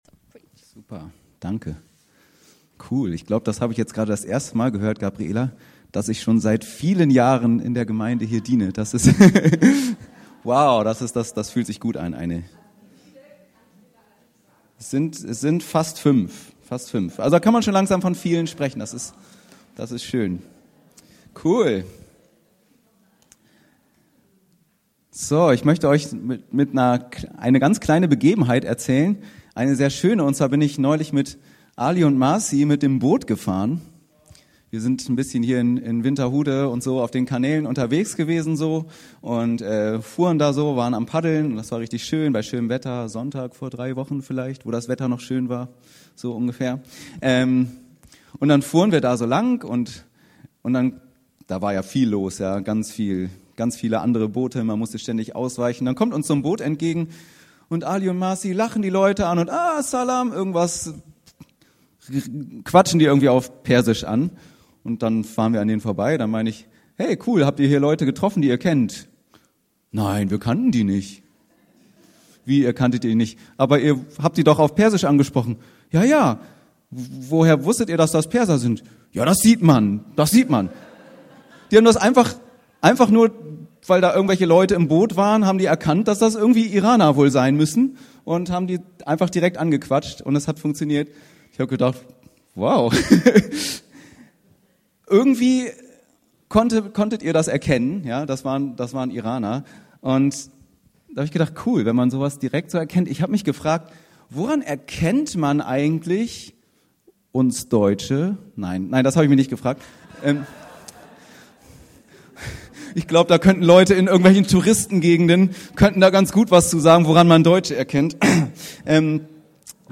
Wie wir konkret Liebe leben können, gerade auch in unserer Unterschiedlichkeit, darum soll es in dieser Predigt gehen.